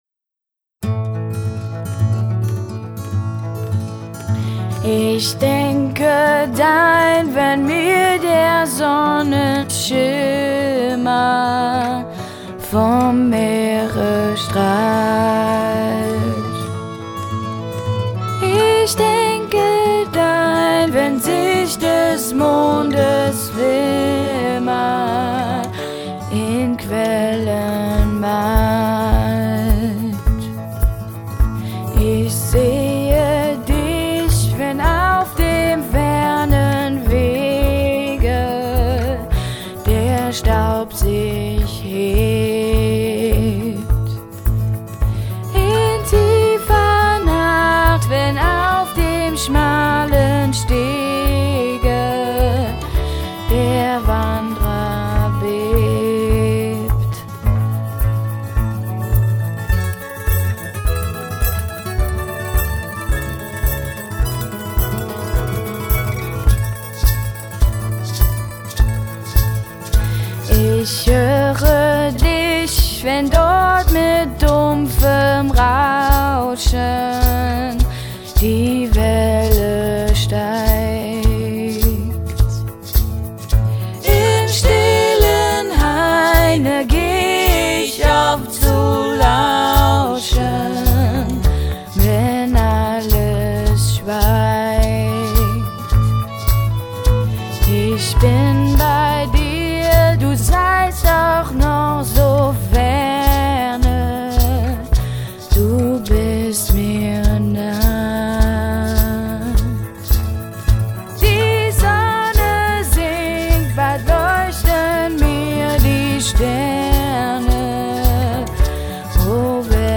Leadvocals